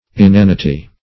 Inanity \In*an"i*ty\, n.; pl.